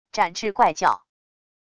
展翅怪叫wav音频